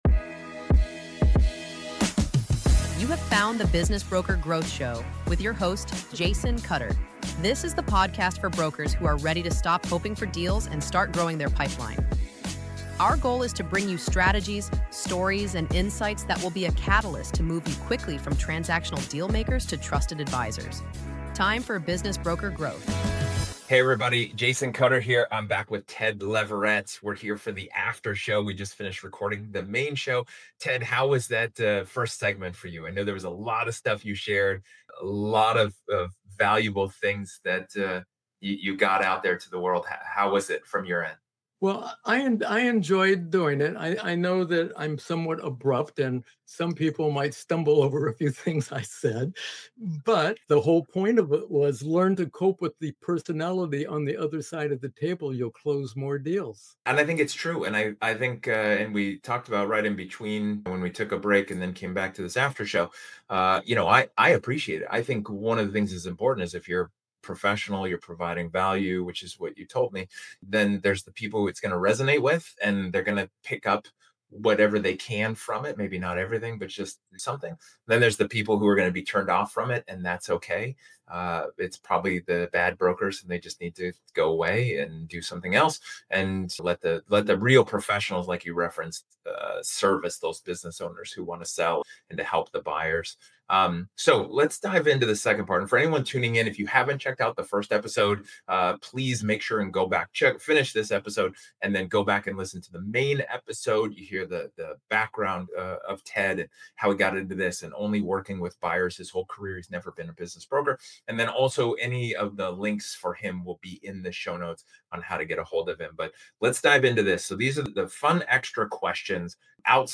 In this after-show conversation, the focus shifts from chasing activity to elevating judgment. The discussion challenges brokers to rethink how they prospect, qualify, and set expectations—because volume alone no longer creates leverage.